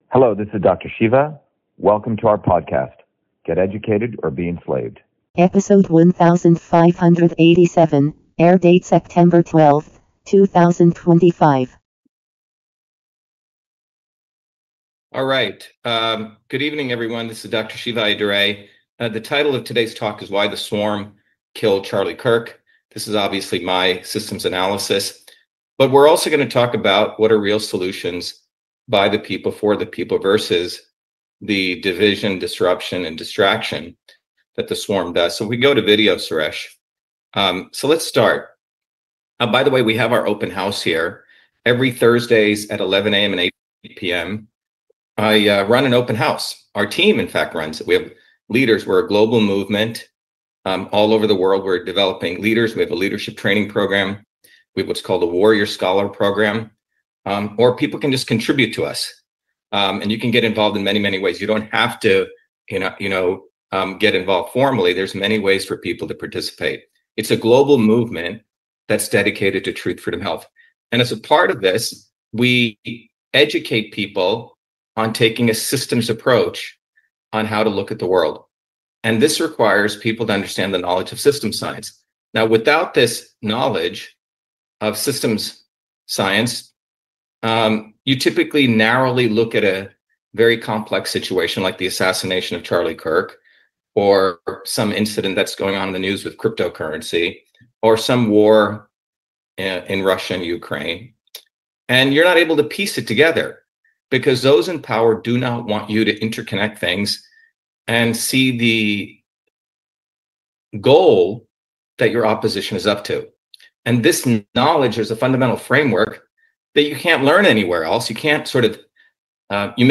In this interview, Dr.SHIVA Ayyadurai, MIT PhD, Inventor of Email, Scientist, Engineer and Candidate for President, Talks about WHY The SWARM Killed Charlie Kirk.